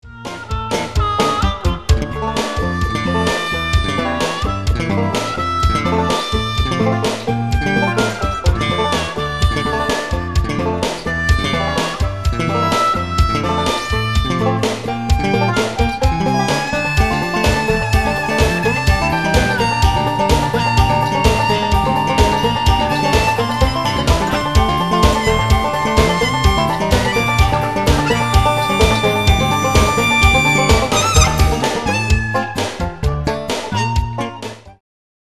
bass
horns
percussion
modern jazz